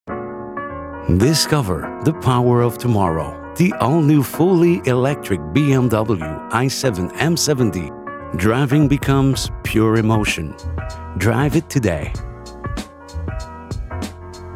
French Accented English Voice Talent